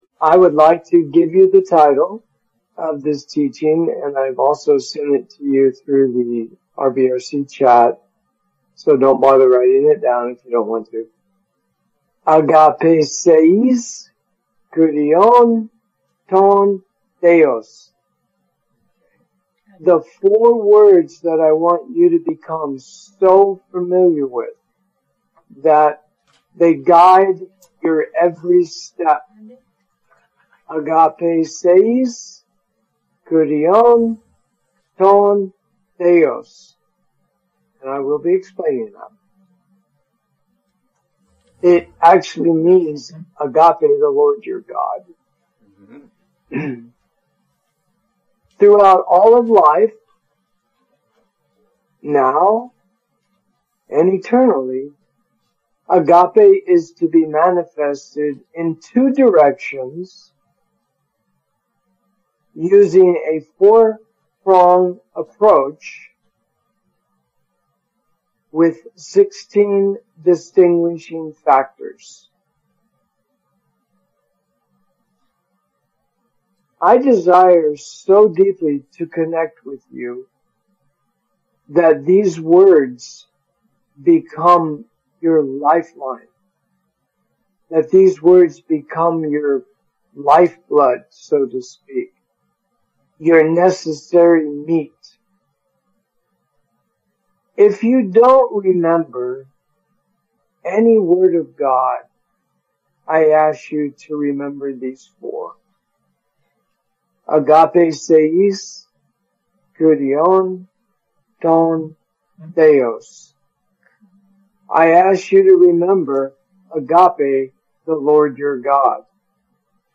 Audio Teaching